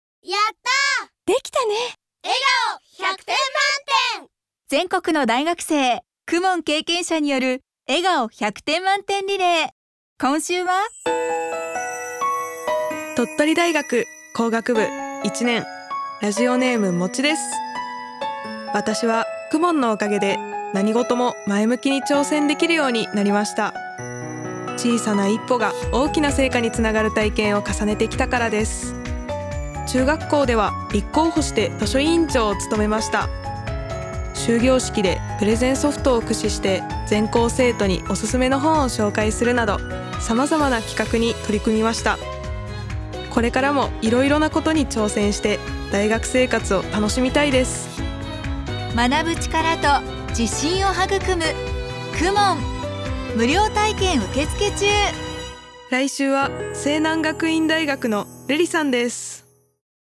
「子どもの頃、KUMONやってました！」･･･という全国の大学生のリアルな声をお届けします。
全国の大学生の声